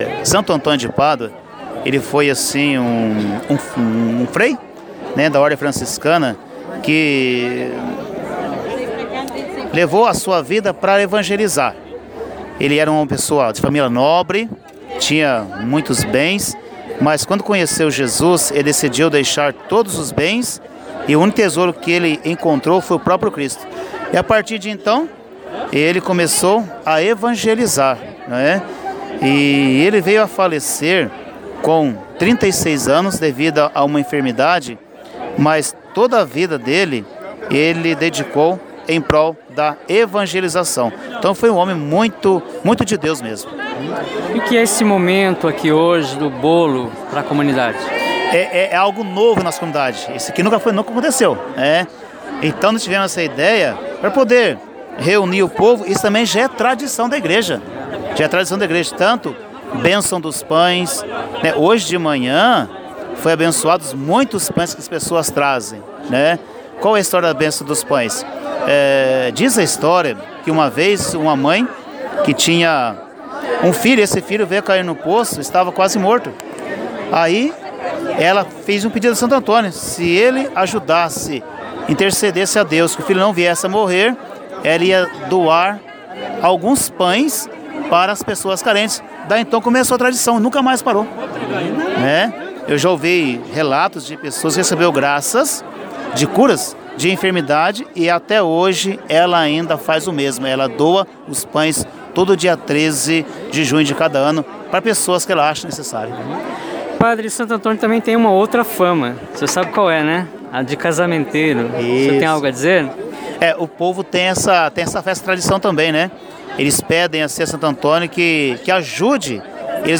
O Paróquia São Sebastião celebra o Dia de Santo Antônio com benção dos pães e bolo